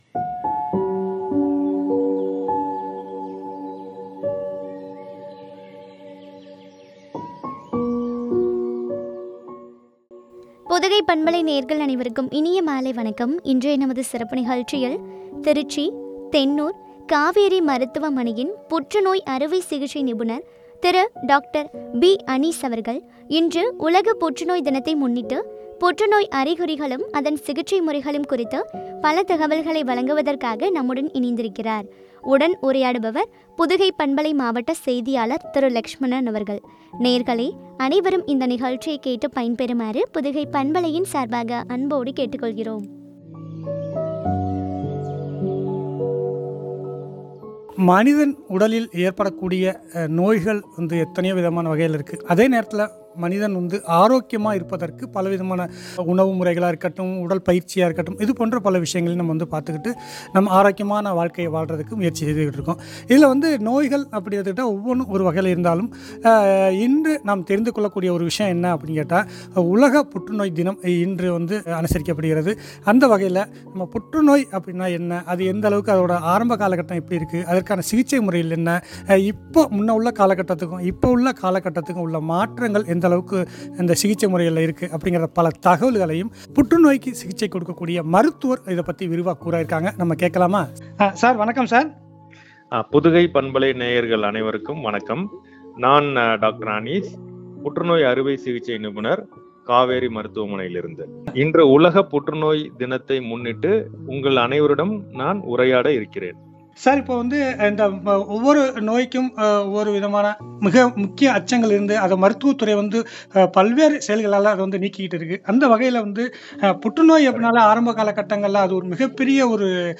சிகிச்சை முறைகளும் பற்றிய உரையாடல்.